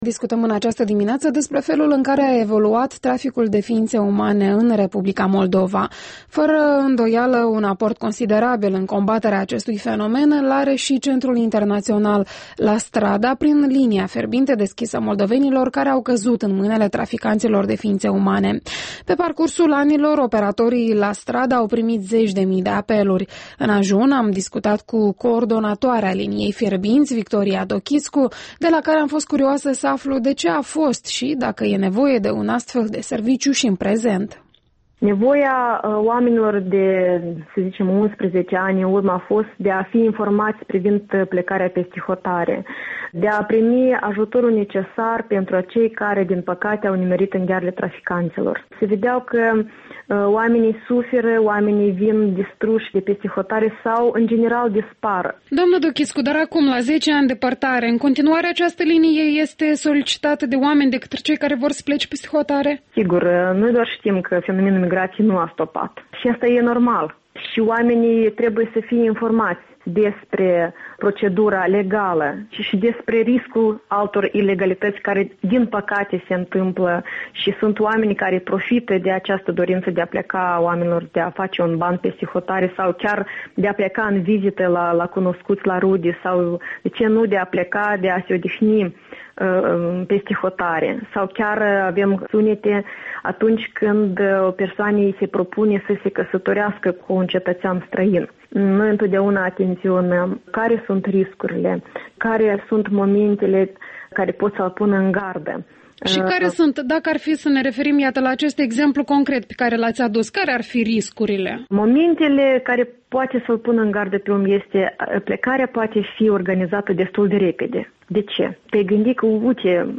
Interviul dimineții